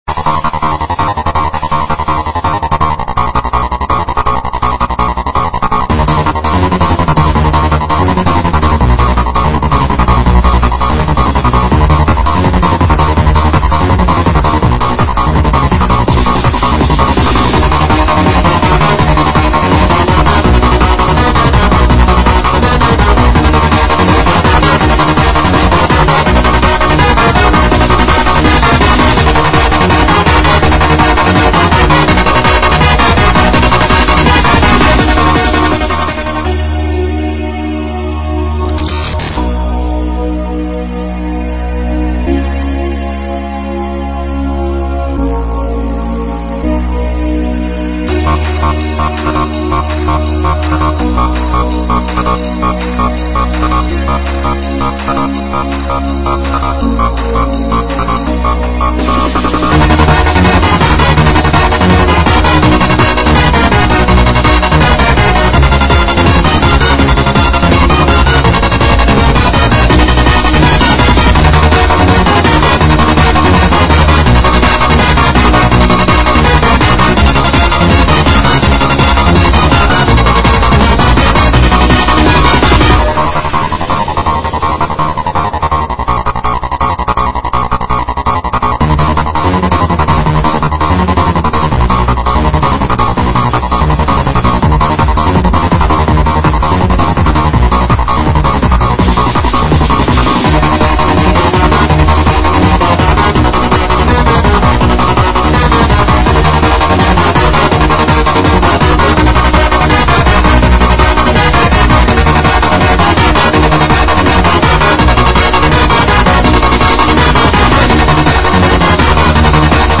An instrumental recording.
I like to call them 'Techcore' demos.